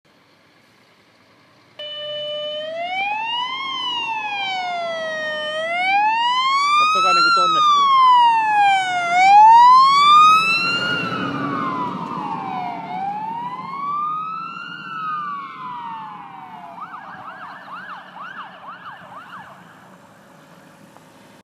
Haluatko kuunnella, miltä paloauton sireeni kuulostaa?